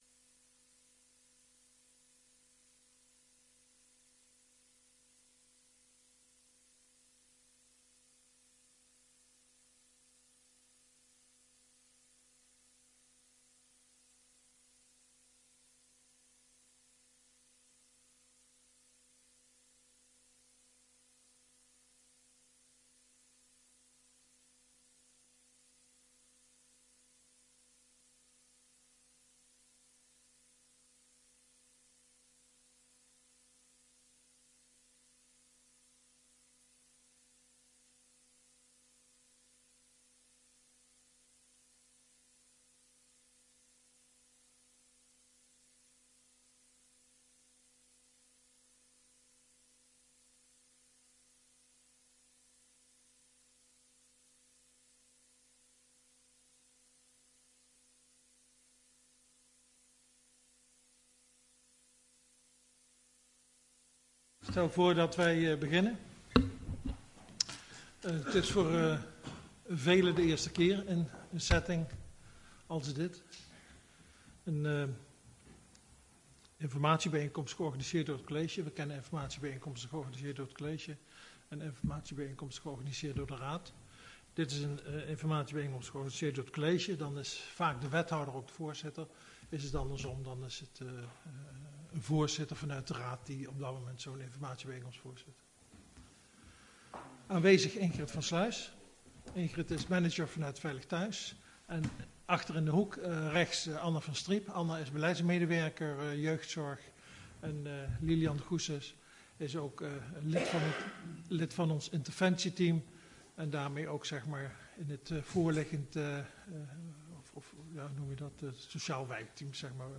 Agenda OverBetuwe - Zaal 3 (oude Raadzaal) dinsdag 5 april 2022 19:30 - 20:30 - iBabs Publieksportaal
CLUSTER INWONERS 1. Informatiebijeenkomst door het college over Voortgang en ontwikkeling Veilig Thuis + terugblik op 2021